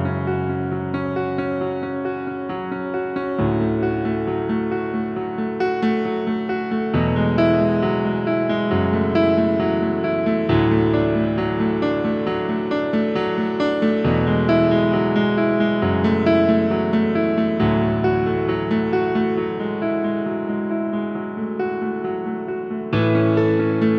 One Semitone Down Pop (2010s) 4:44 Buy £1.50